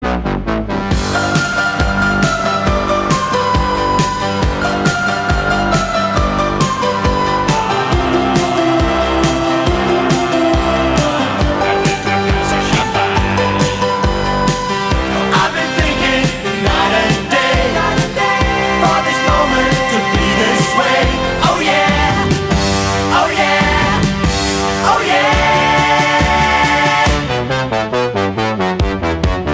Gattung: Moderner Einzeltitel
Besetzung: Blasorchester
Tonart: F-Dur